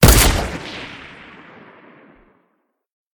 mgun1.ogg